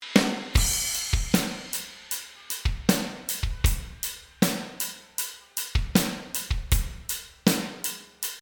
And now, just to make the snare pop out a little more, let’s add some plate reverb to only the snare.
Now, this is a subtle difference, and the snare is also going to the hall reverb.
drumshallplate.mp3